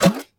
ギャグ・アニメ調（変な音）
食虫植物に食われる